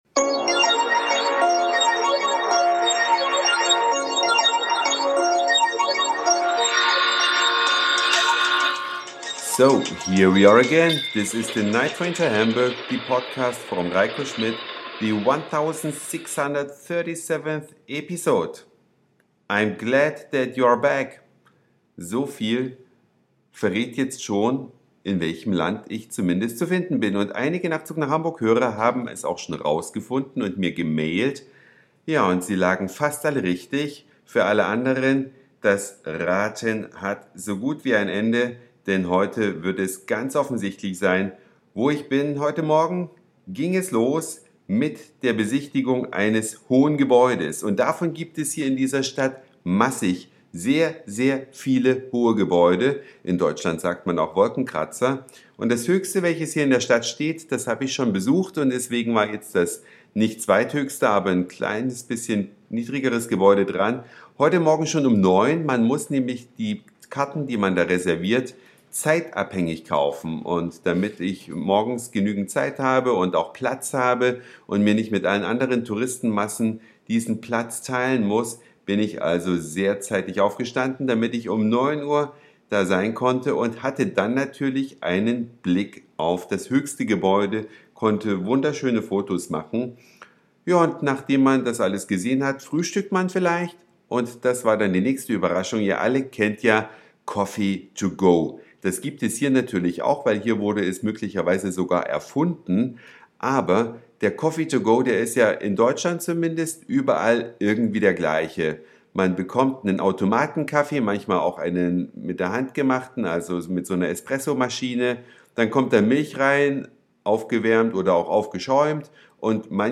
Eine Reise durch die Vielfalt aus Satire, Informationen, Soundseeing und Audioblog.